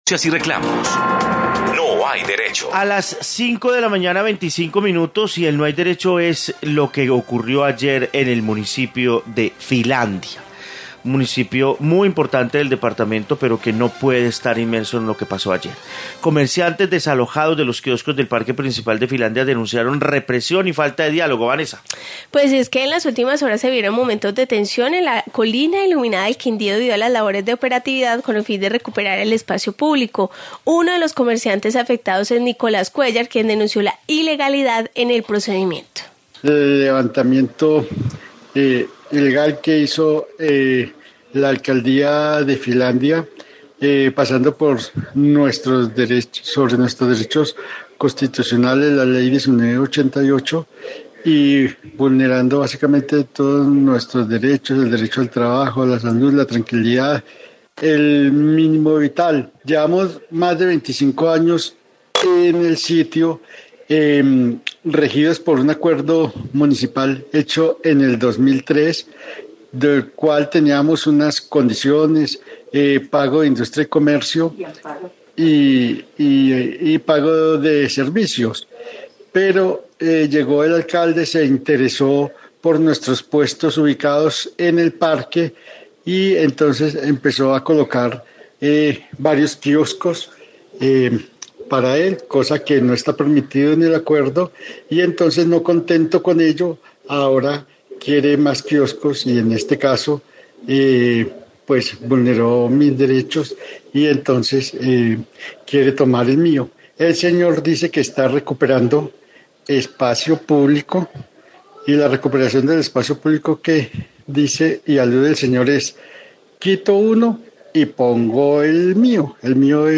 Informe caso desalojo en Filandia